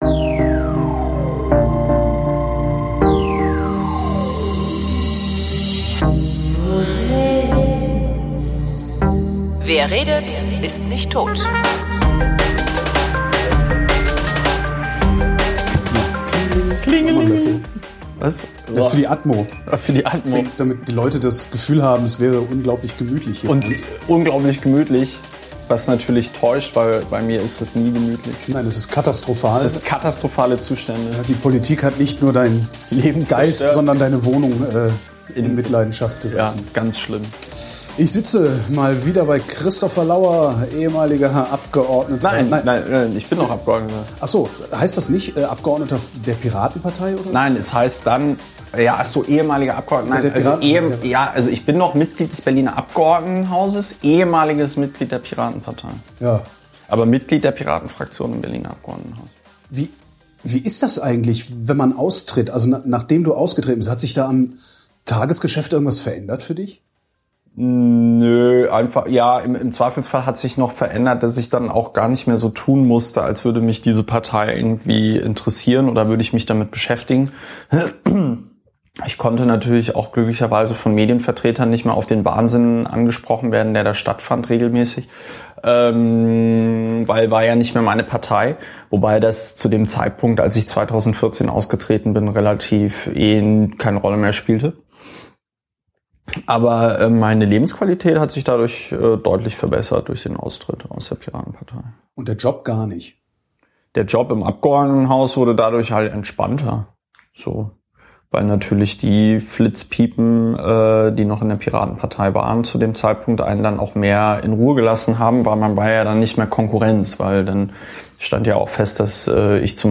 Nach vielen Monaten bin ich mal wieder zu Christopher Lauer gefahren, um mit ihm zu reden. Diesemal über Kriminalitätsbelastete Orte, die Rigaer Straße, eine Verfassungsklage auf Akteneinsicht, Frank Henkel, die Polizei, die Berliner Abgeordnetenhauswahl 2016, die Innenverwaltung und unabhängige Kandidatur.